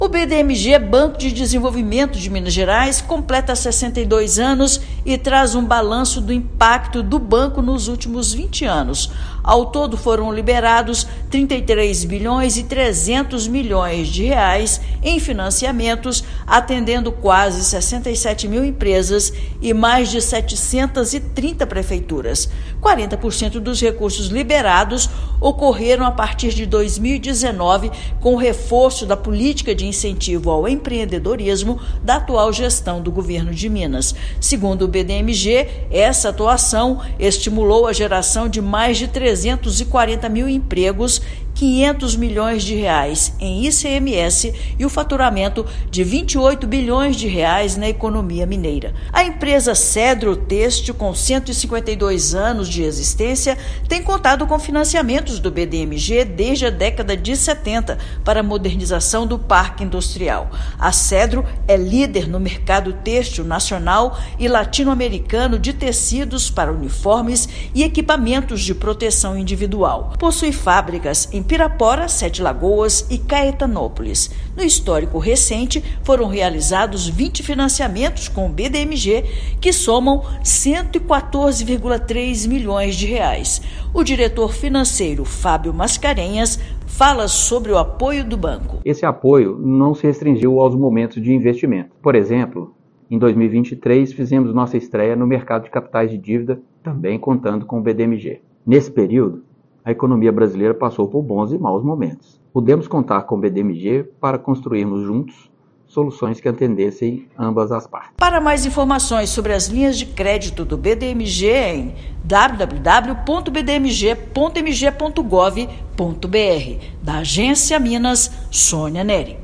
[RÁDIO] BDMG libera R$ 33,3 bilhões em crédito nas últimas décadas, com 40% executados a partir de 2019
Comemorando 62 anos neste mês, balanço mostra que financiamentos estimulam a geração de empregos no estado. Ouça matéria de rádio.